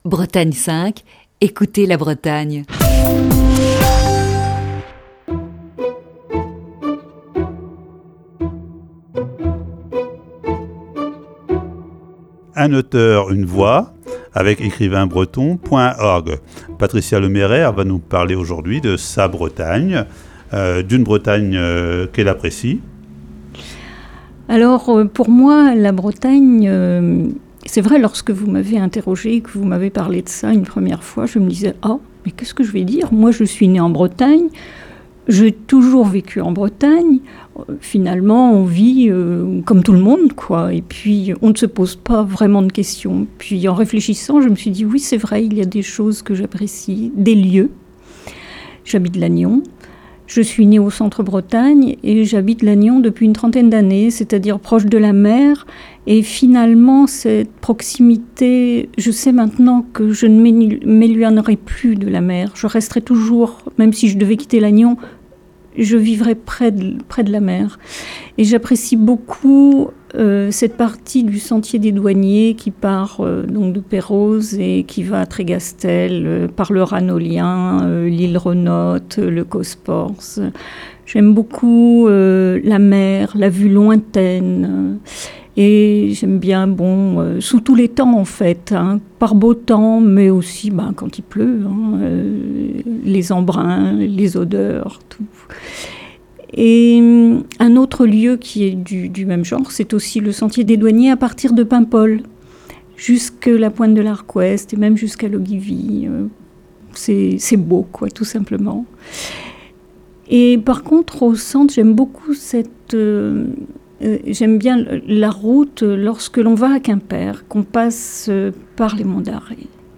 Ce matin, deuxième partie de cet entretien.